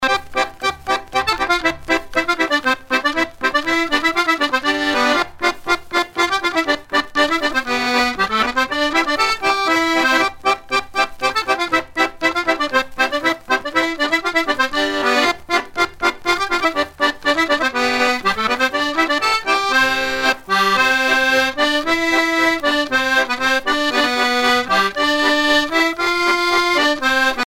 air provenant de la région d'Anost
danse : polka
Pièce musicale éditée